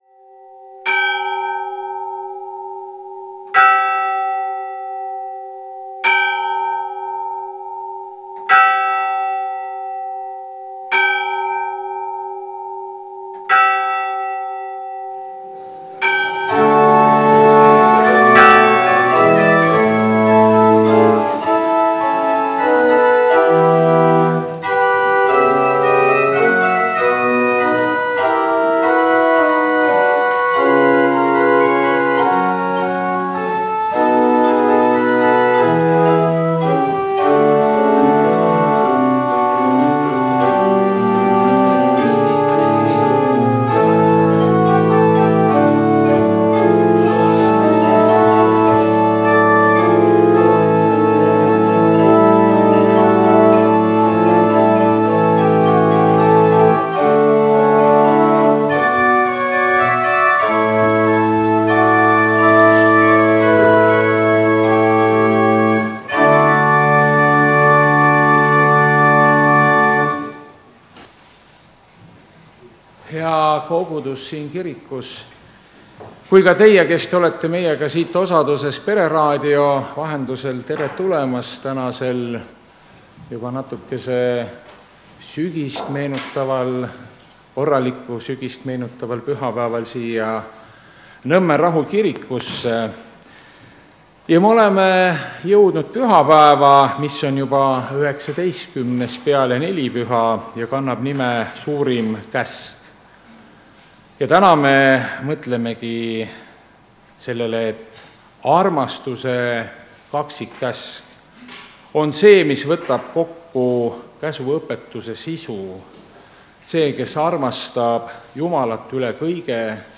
Jumalateenistus 19. oktoober 2025